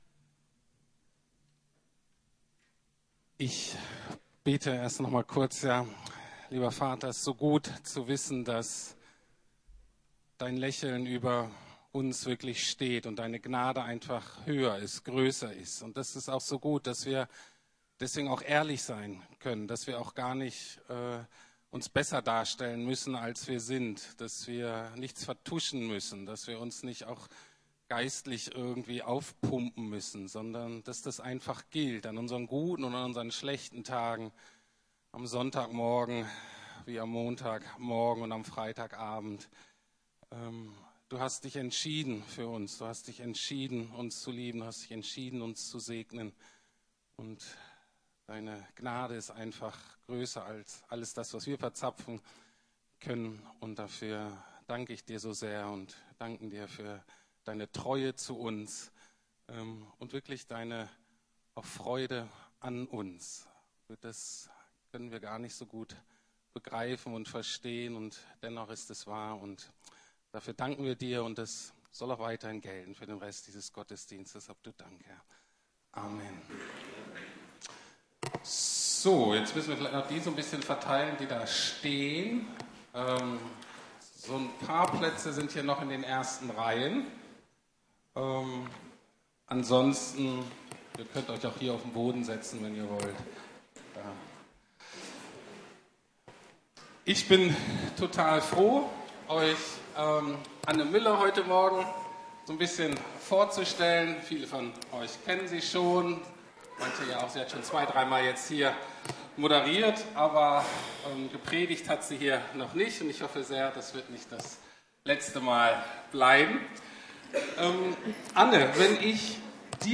Lieben was Jesus liebt - Familie ~ Predigten der LUKAS GEMEINDE Podcast